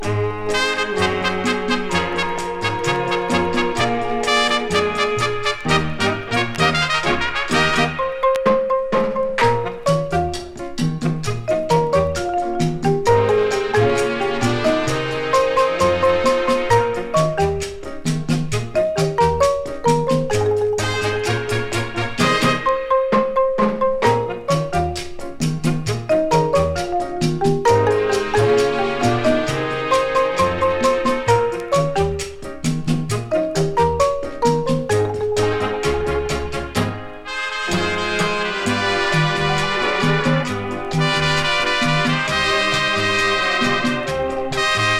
熱さもあり、涼やかさもあり、流暢でキレのある演奏はバンドの充実度が高く魅力いっぱいです。